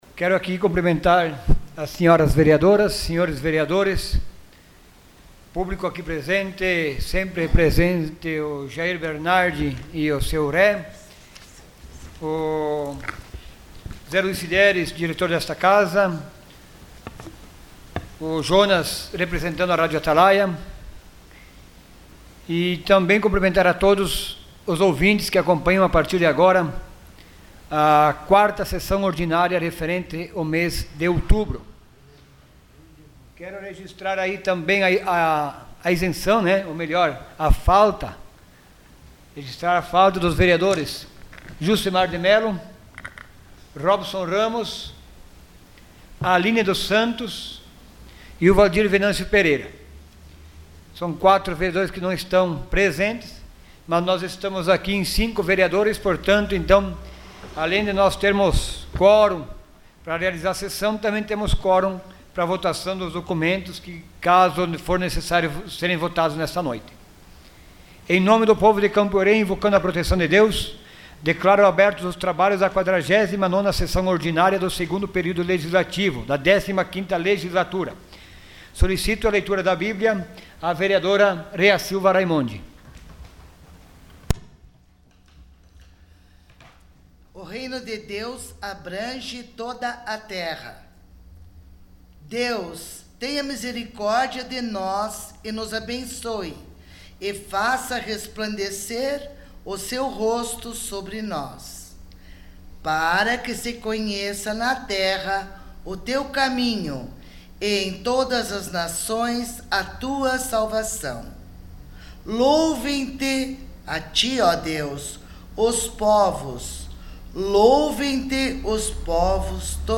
Sessão Ordinária dia 22 de outubro de 2018.